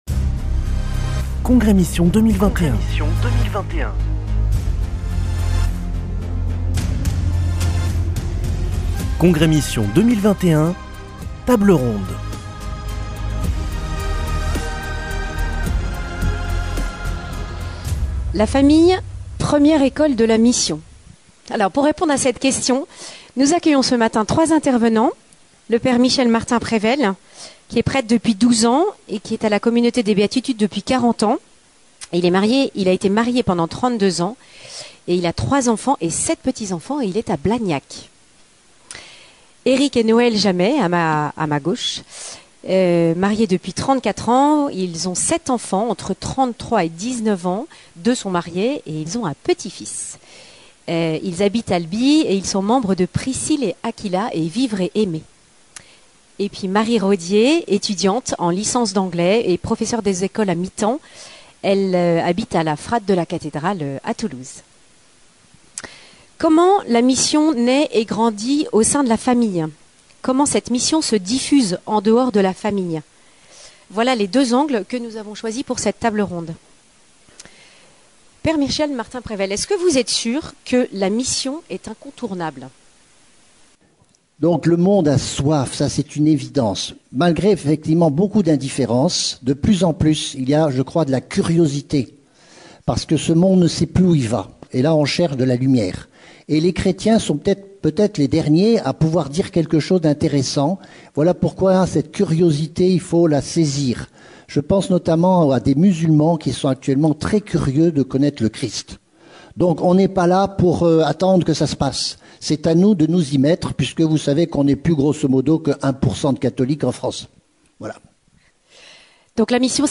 Congrès Mission du 1er au 3 octobre à Toulouse - Table ronde 2